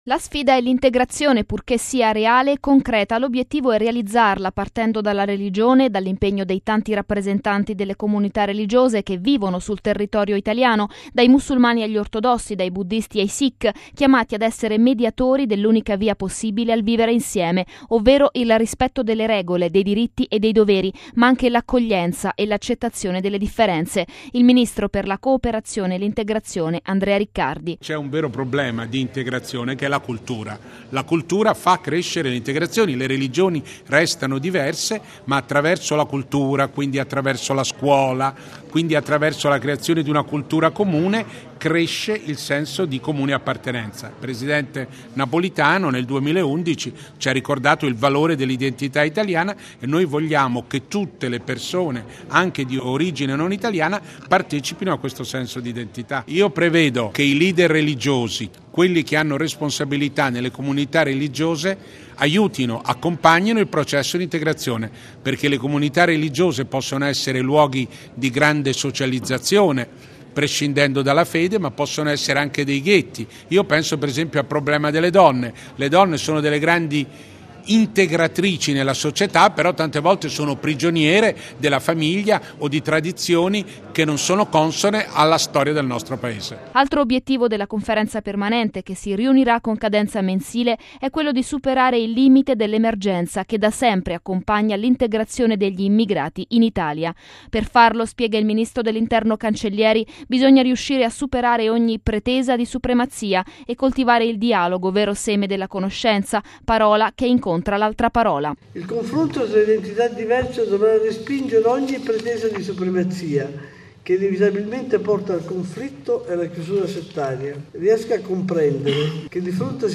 Il ministro per la Cooperazione e l’Integrazione, Andrea Riccardi:
Quale la ricetta del governo sul fronte immigrazione? Ancora il ministro Cancellieri: